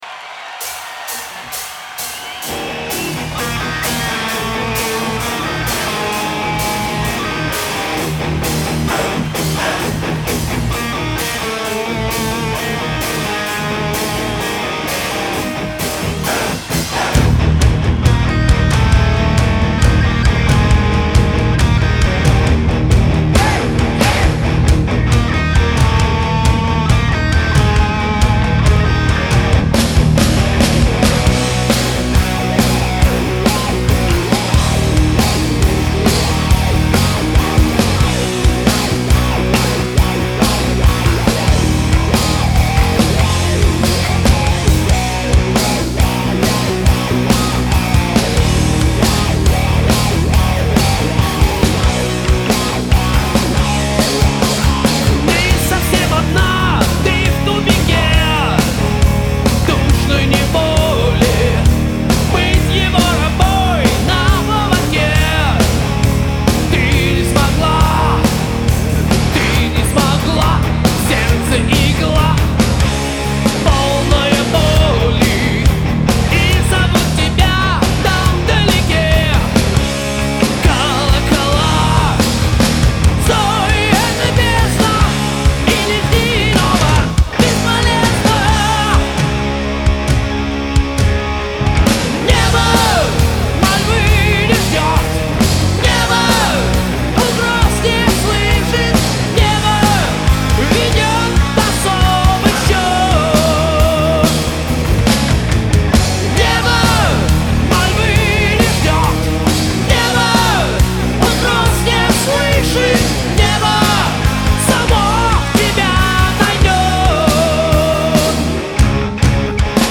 Жанр: Heavy Metal